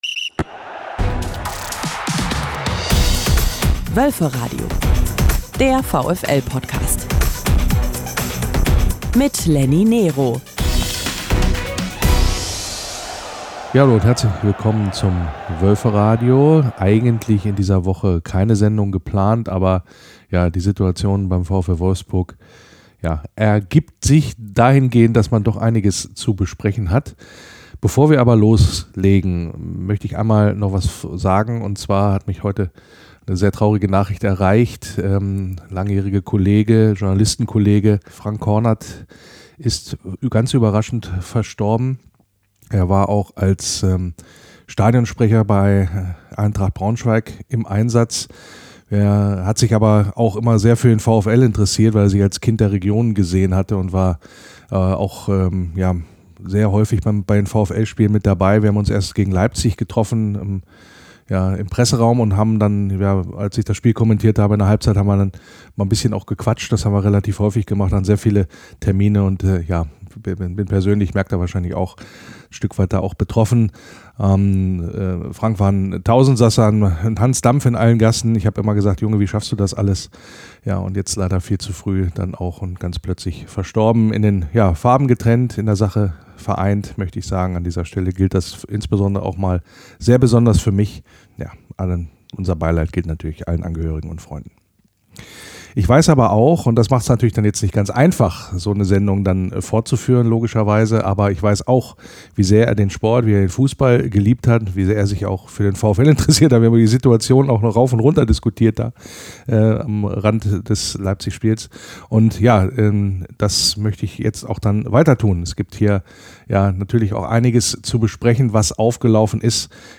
nähern sich der Thematik noch mal mit einem Grundsatzgespräch in der eigentlich Wölferadio-freien Länderspielpause: Was ist nur los mit diesem Verein?